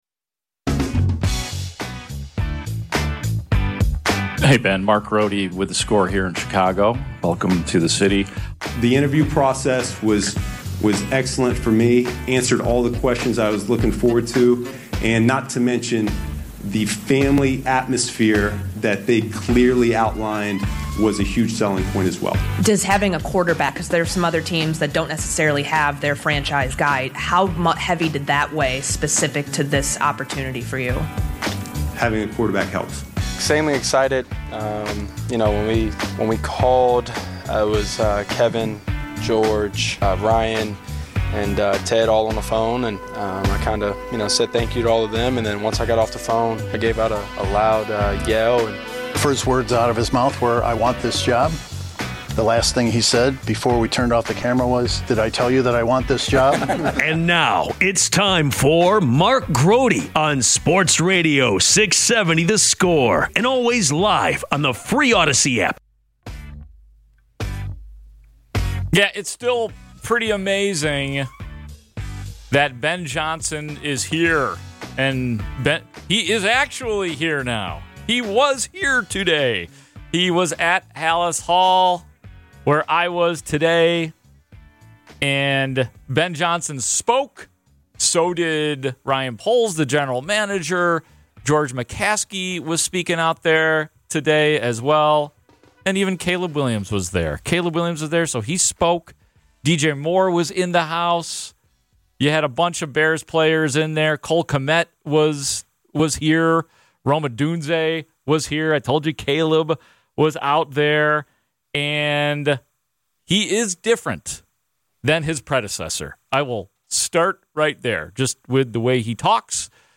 Best of the Score brings listeners the best interviews, segments, bits and highlights of the station's many shows